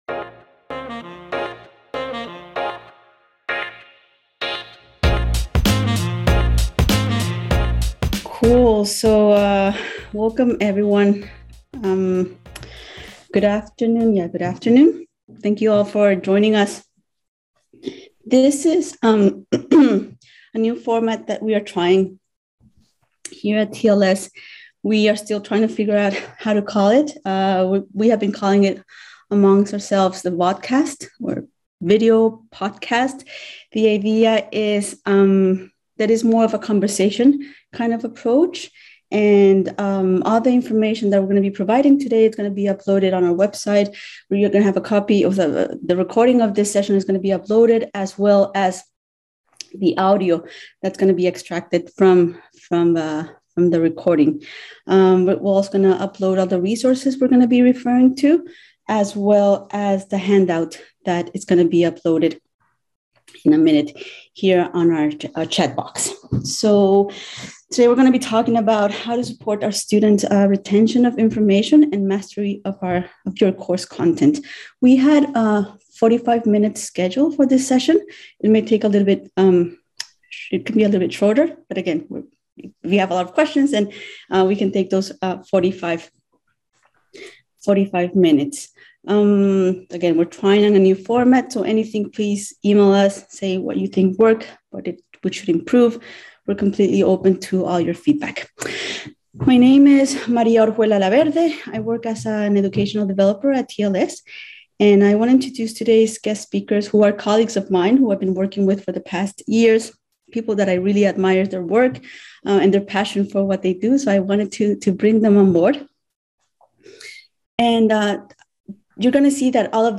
In this webinar, we will introduce evidence-informed teaching strategies, such as retrieval, spacing and interleaving practices, that support students’ mastery of course content. Strategies introduced in this webinar require minimal preparation and little or no grading.